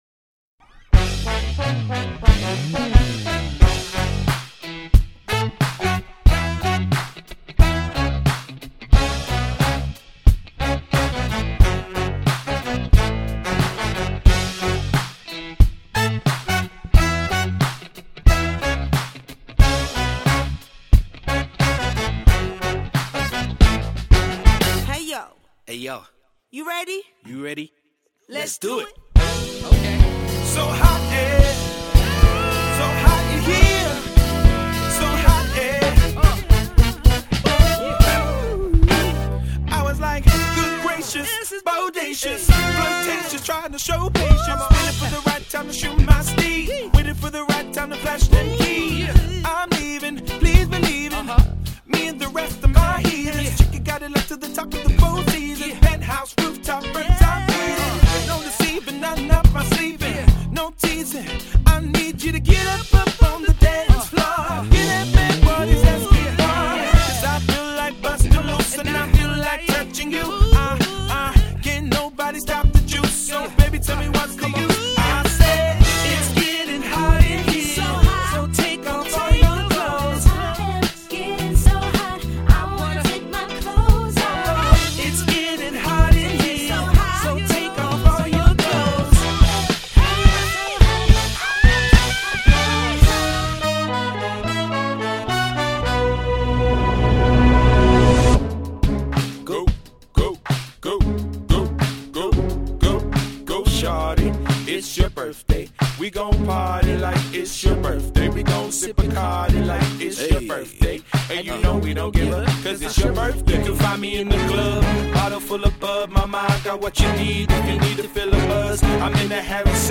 specialising in RnB, Soul and Hip Hop